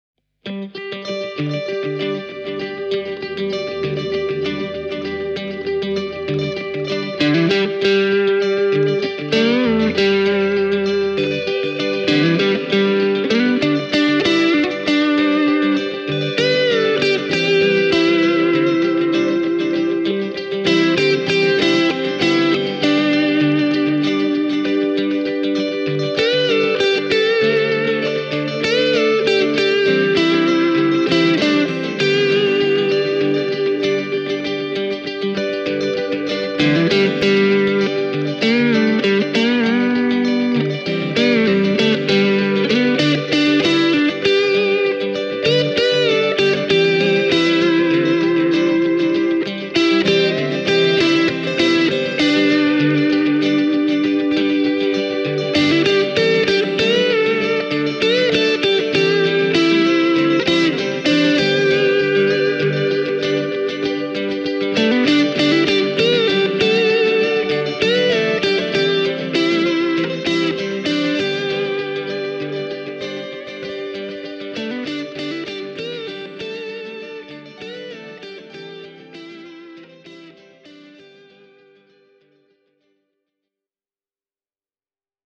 Demokappaleessa Telecasterilla soitetut komppiraidat ovat stereokuvassa vasemmalla, ja Stratocasterin komppiosuudet oikealla. Ensimmäinen soolo-osuus on soitettu Telellä, kun taas toisessa soi Strato.